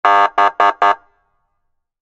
Звуки полицейской крякалки